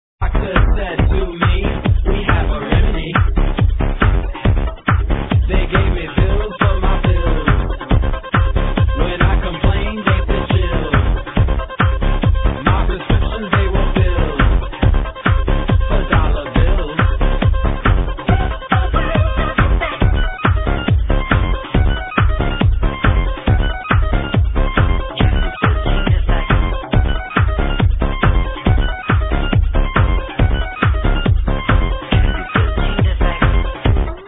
Plz ID this killer tech-house track!